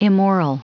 Prononciation du mot immoral en anglais (fichier audio)
Prononciation du mot : immoral